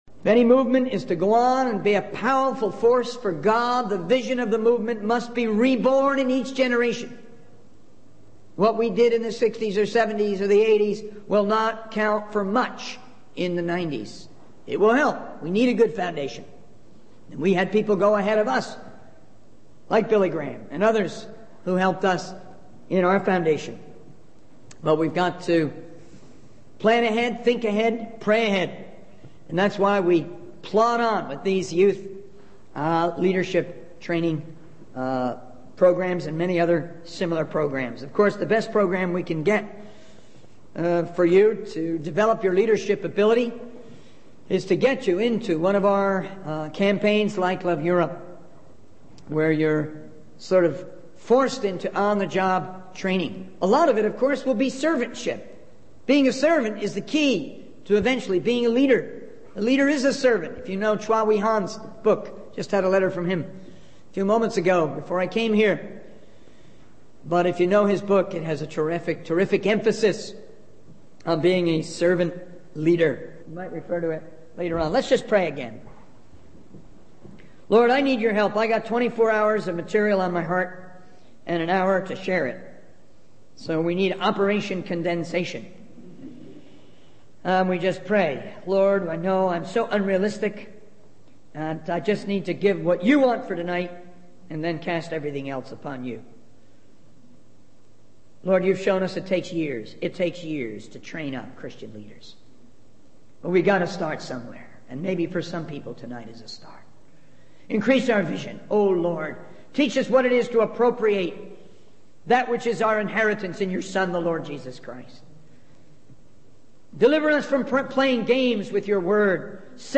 In this sermon, the speaker emphasizes the importance of leadership and motivating others in the context of spreading the word of God. He encourages the audience to take ownership and become mobilizers for the kingdom of God.